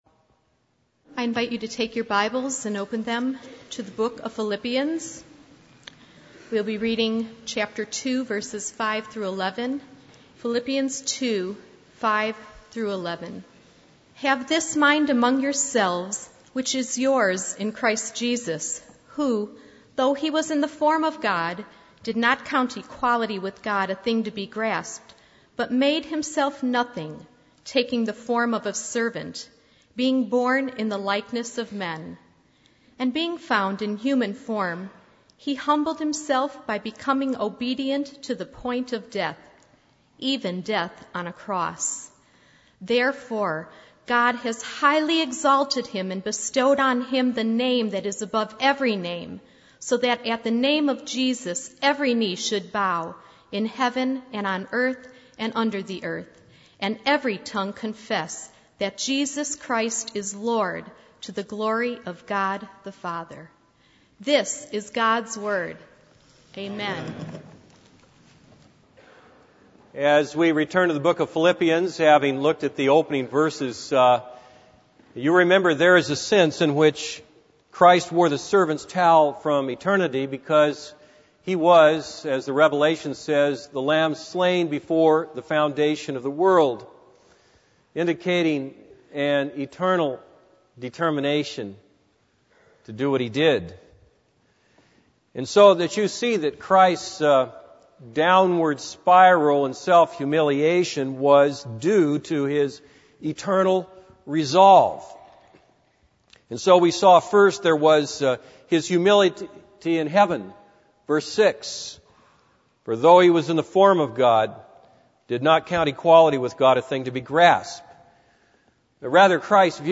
This is a sermon on Philippians 2:9-11.